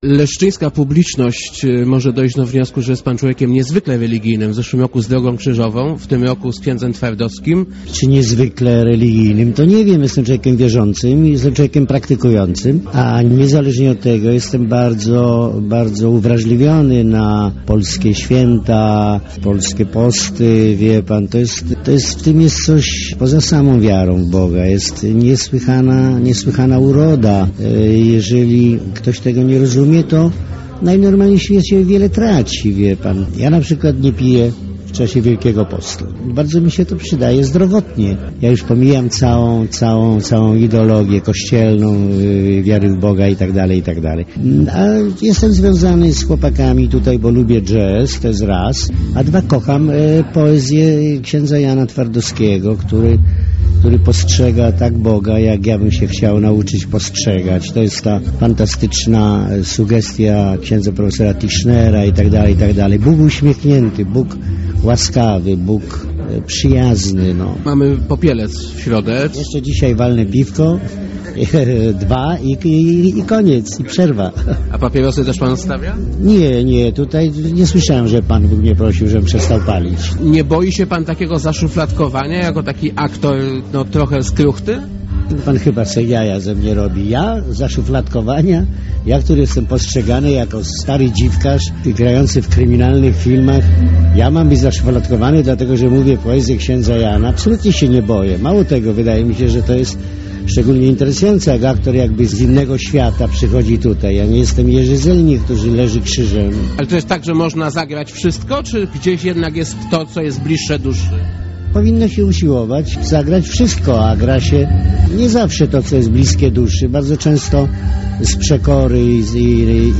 thumb_jnowicki.jpgLeszno. Z widowiskiem “Z obłoków na ziemię” wystąpił na deskach Centrum Kultury i Sztuki w Lesznie znany krakowski aktor Jan Nowicki.